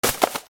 ビニール袋が地面に落ちる
/ J｜フォーリー(布ずれ・動作) / J-10 ｜転ぶ　落ちる